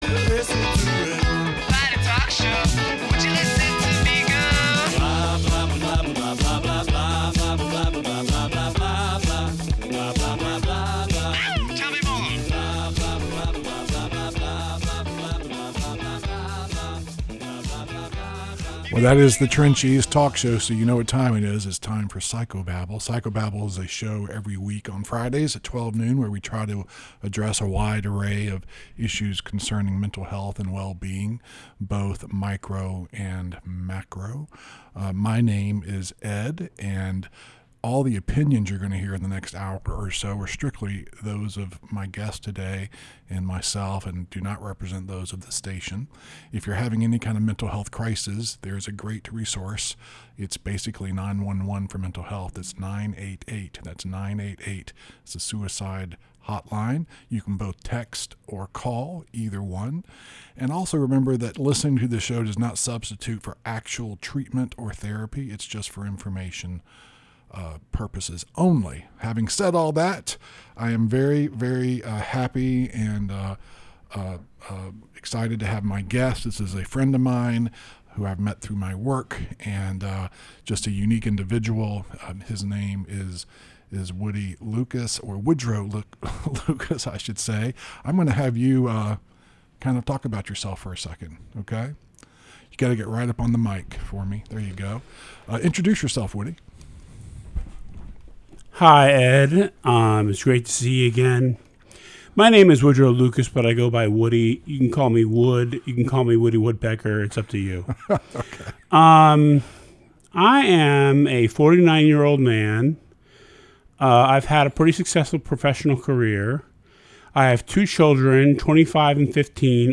Featured Audio/Podcast Interview